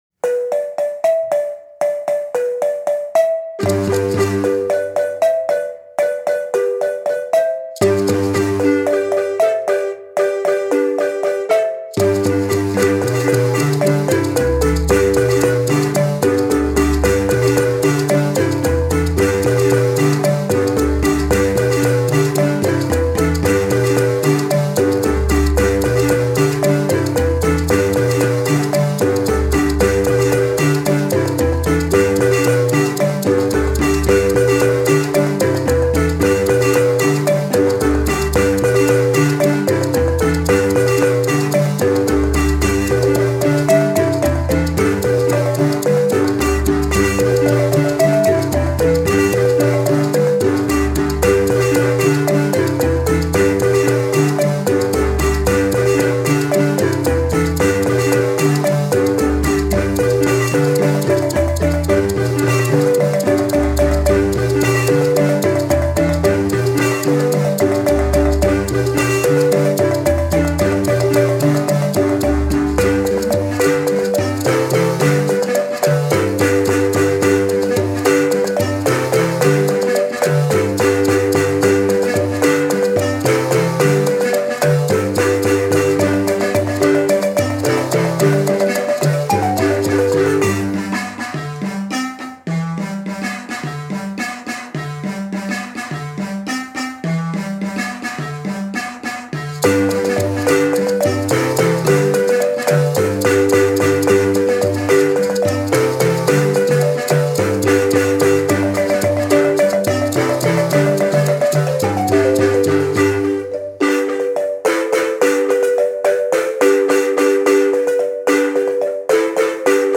Zimbabwean marimba